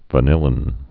(və-nĭlĭn, vănə-lĭn)